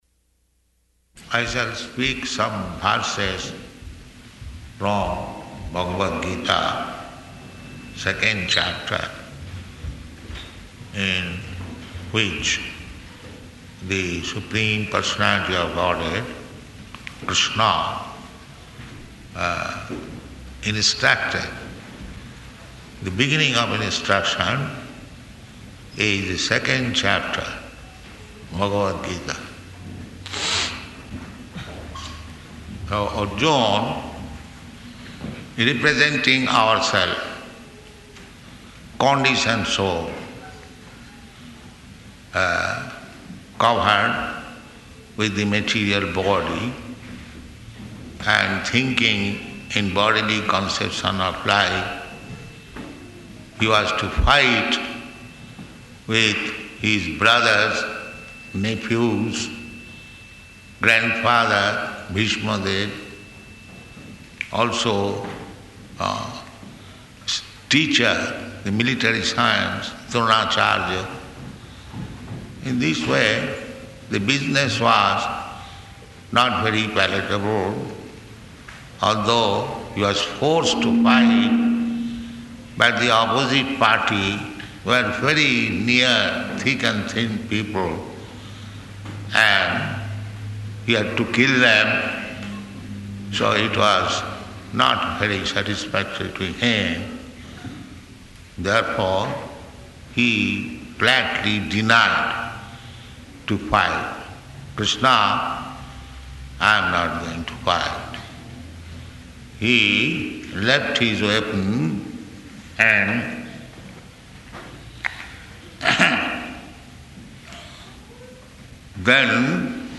Location: Johannesburg